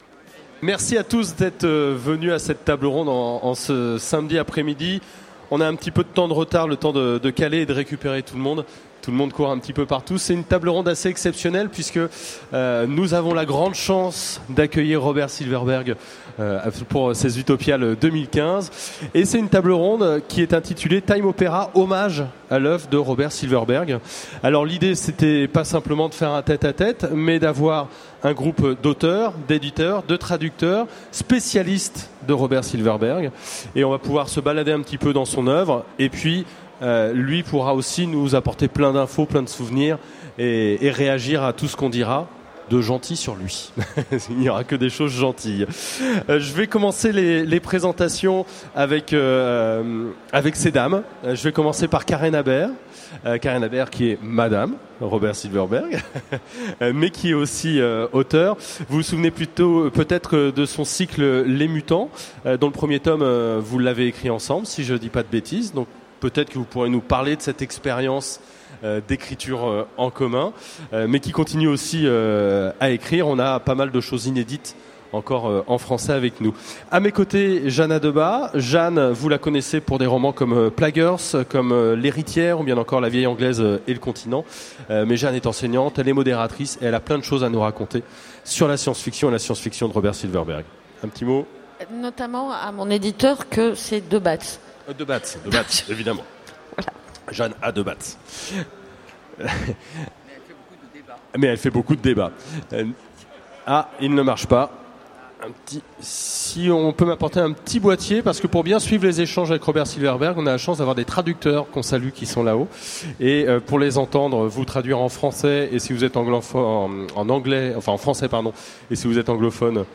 Utopiales 2015 : Conférence Time Opera